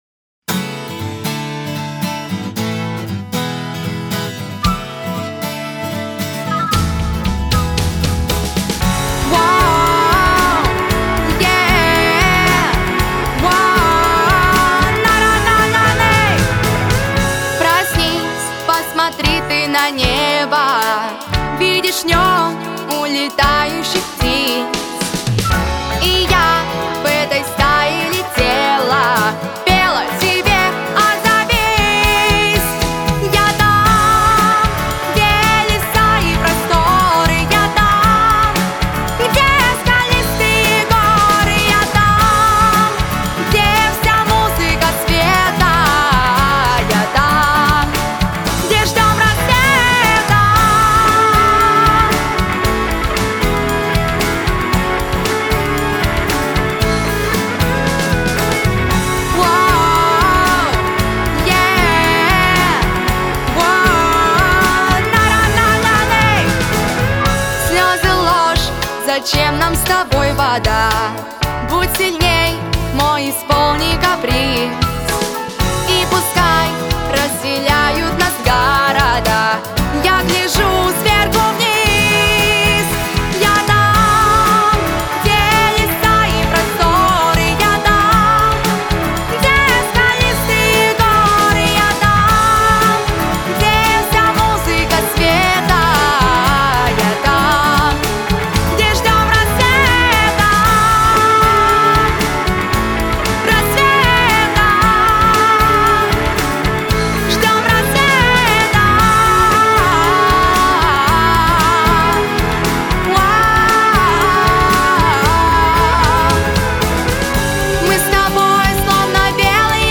• Категория: Детские песни
подростковые песни